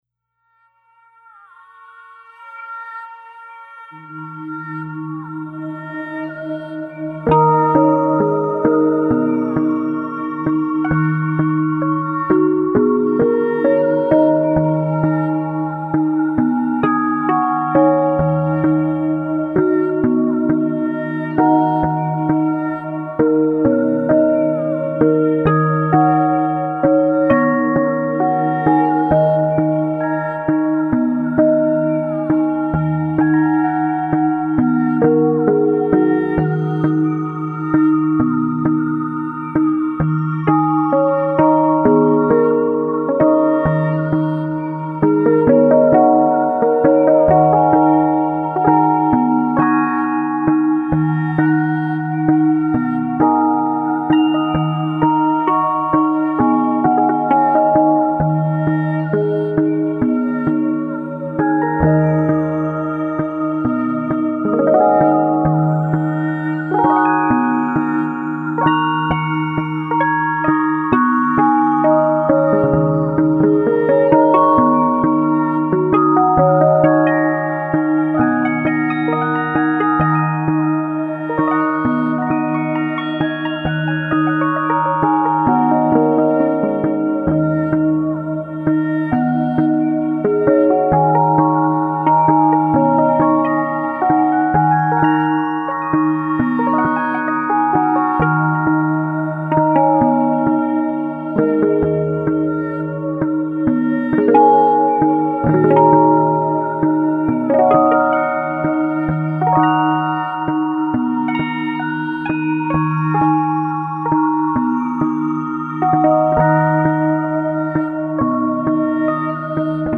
幻想的なシンセパッドと、アジアンテイストな民族楽器のメロディーが特徴の楽曲です。